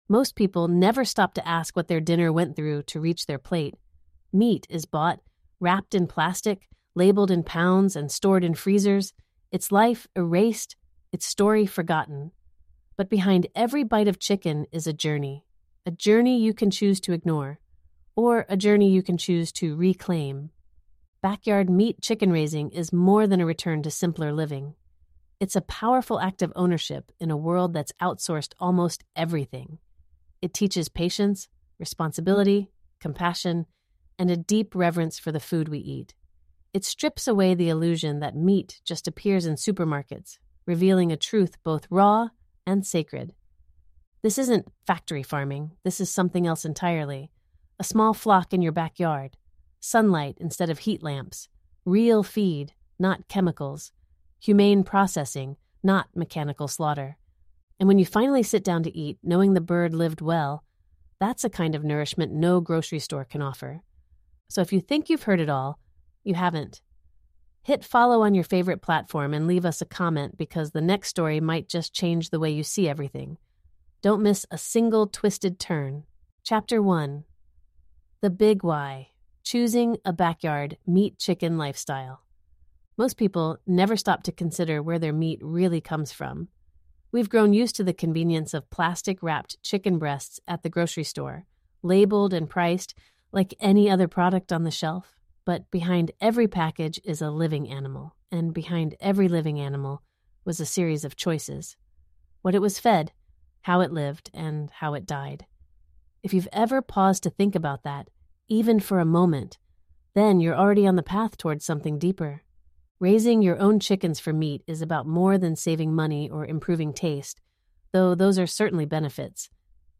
Discover the timeless art of raising chickens for meat — from selecting the perfect breed to the final harvest, this cinematic, step-by-step guide brings homesteading wisdom to life for the modern backyard beginner. Told in the warm tone of a seasoned farmer, this 20-chapter journey breaks down everything: ethical butchering, organic feed recipes, daily growth schedules, and hands-on tips that challenge the factory-farming norm.